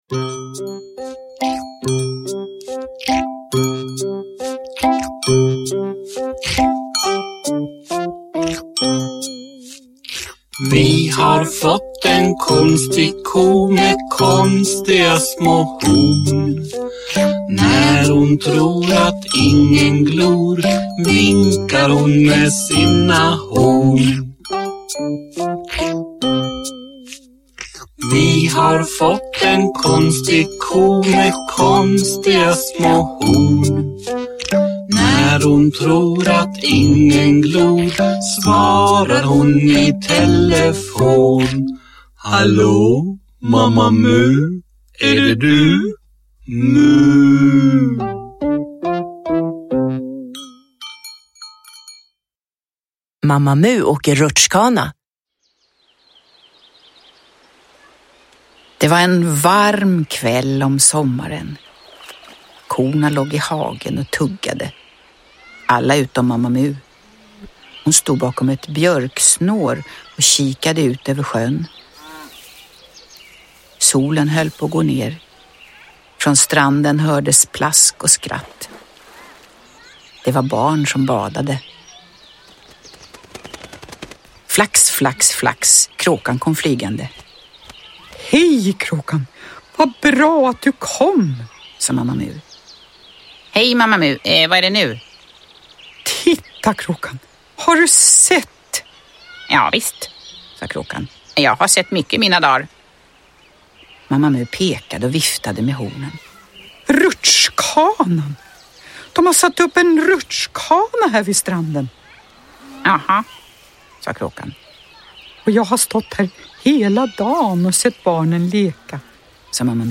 Mamma Mu åker rutschkana – Ljudbok – Laddas ner
Uppläsning med musik.
Uppläsare: Jujja Wieslander